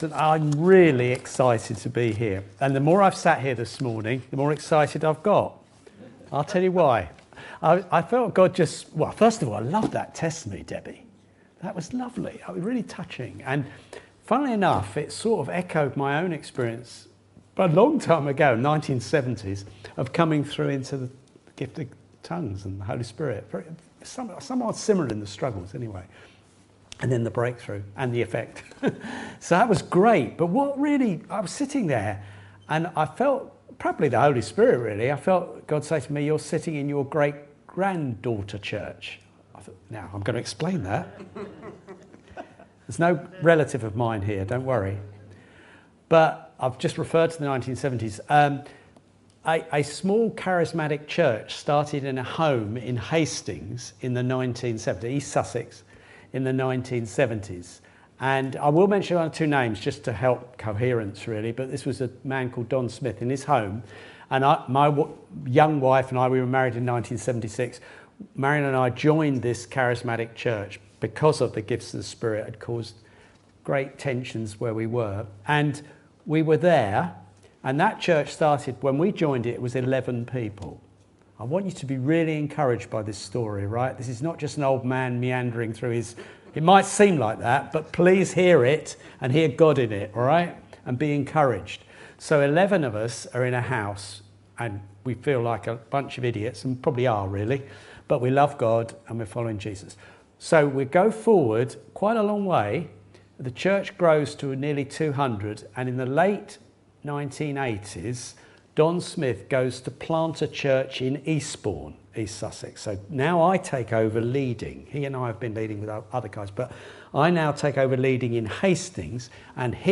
This sermon reminds us through the story of Hagar that God remains close to his people.